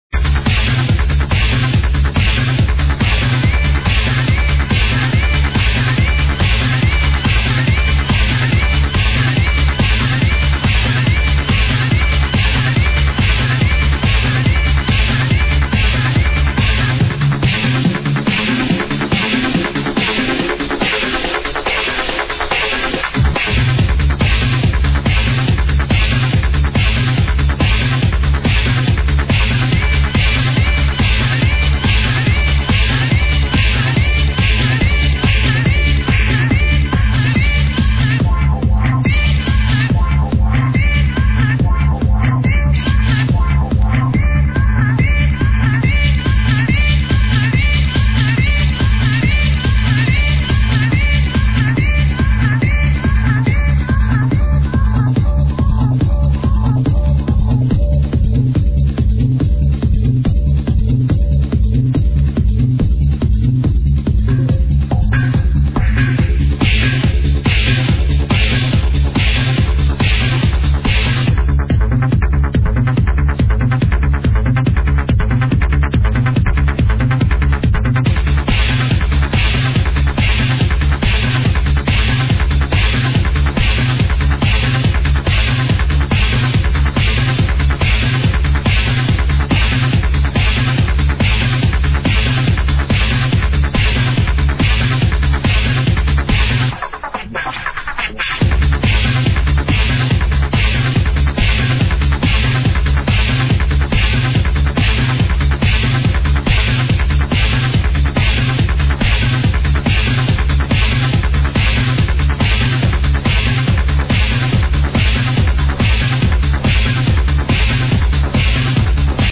check it out...u cant miss the vocals....